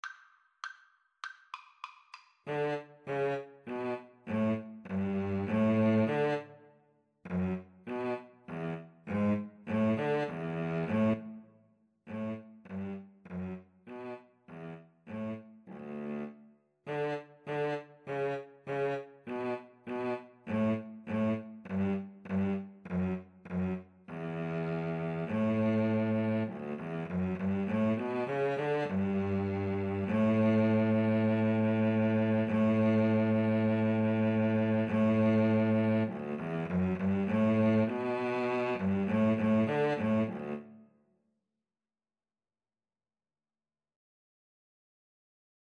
Free Sheet music for Viola-Cello Duet
D major (Sounding Pitch) (View more D major Music for Viola-Cello Duet )
Allegro =200 (View more music marked Allegro)
Classical (View more Classical Viola-Cello Duet Music)